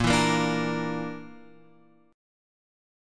A# Major Flat 5th